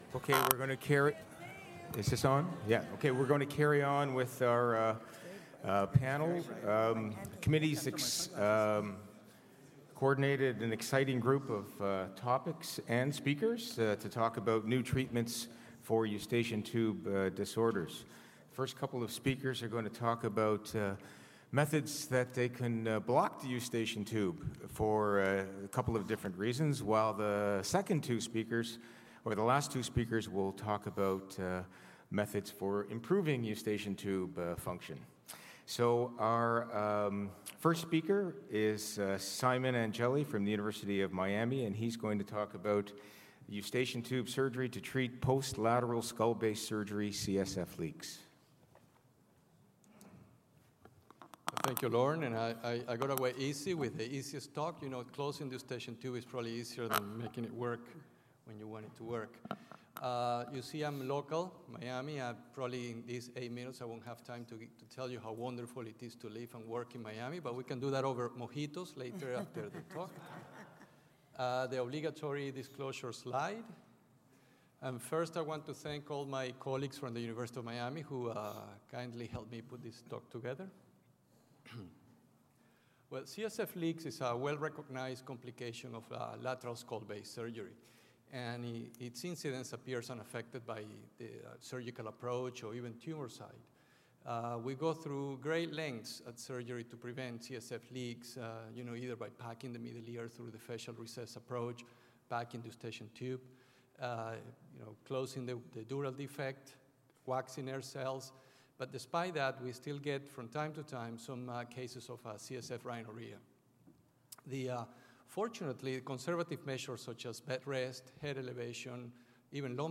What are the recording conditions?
A panel of experts at the Triological Society's 2014 Combined Sections Meeting discuss their worst cases, and how they managed them.